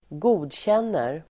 Uttal: [²g'o:dtjen:er]